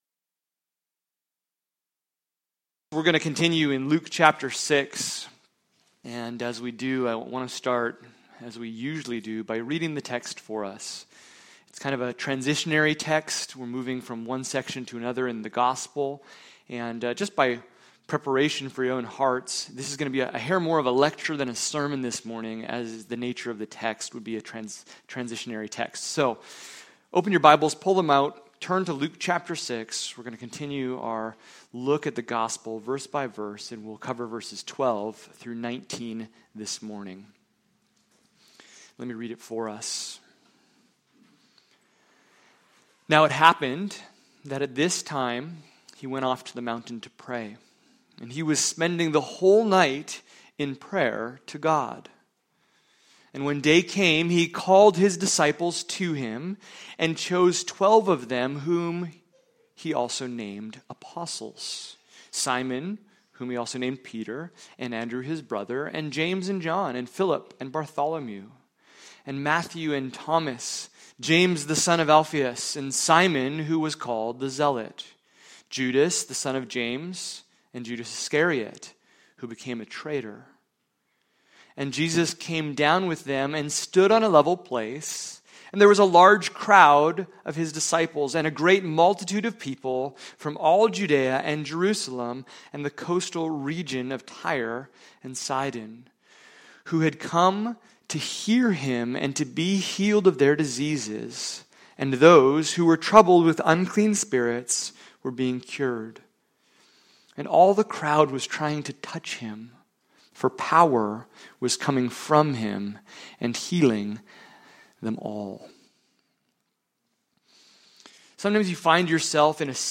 Luke 6:12-19 Service Type: Sunday Morning « Jesus